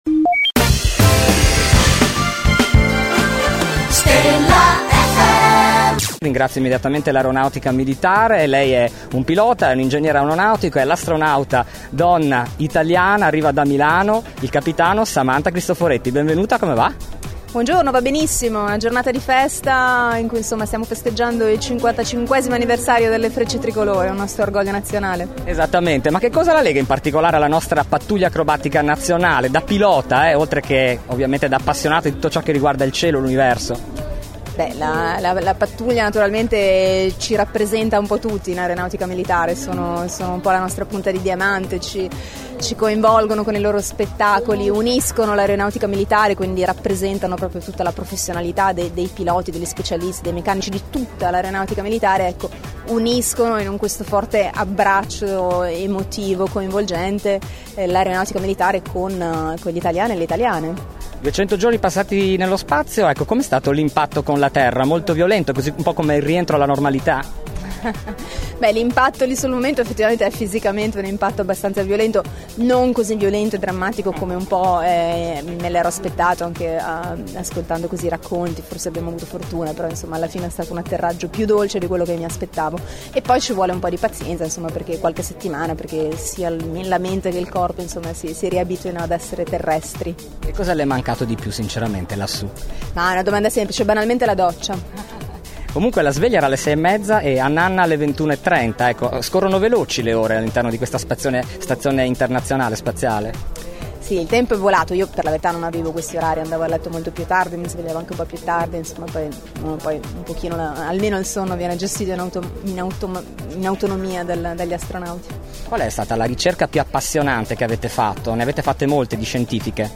Intervista Samantha Cristoforetti | Stella FM